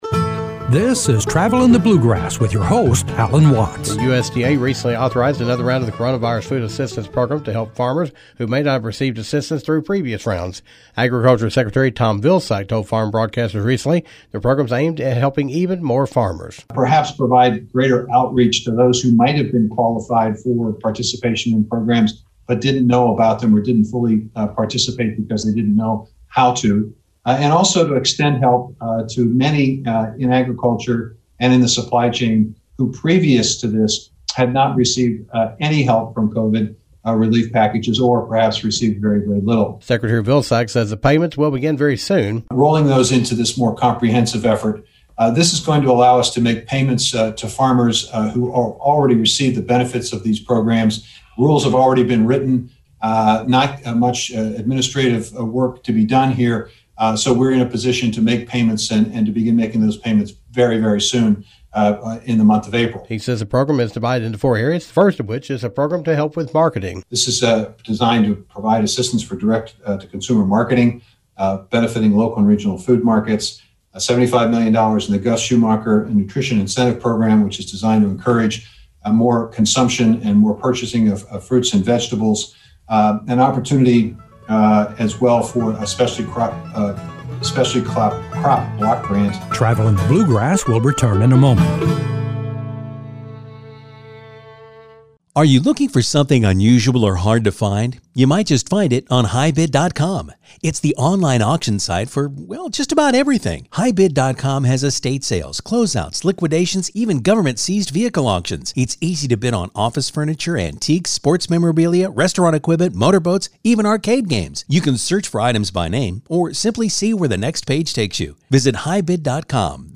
Newly appointed Secretary of Agriculture Tom Vilsack says farmers will be eligible for another round of Coronavirus Food Assistance Program payments. Secretary Vilsack talks about the payments, the farmers that eligible, and other details.